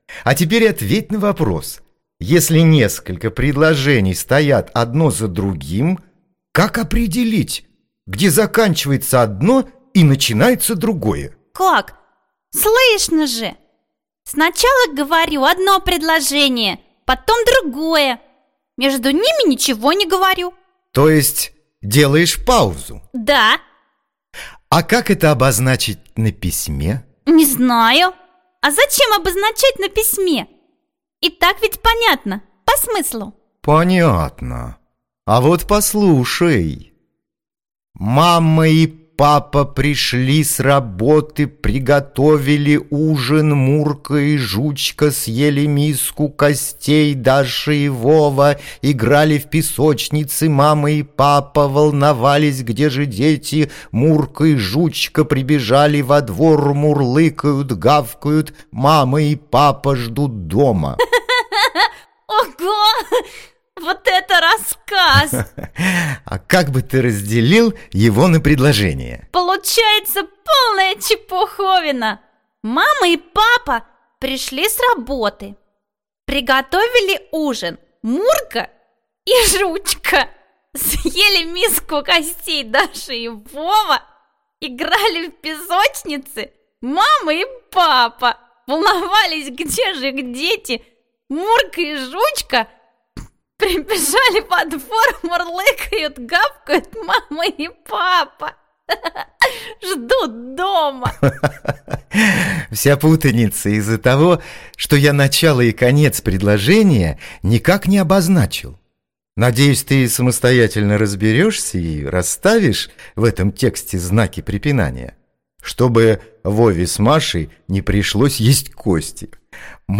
Аудиокнига Знаки препинания. Части речи | Библиотека аудиокниг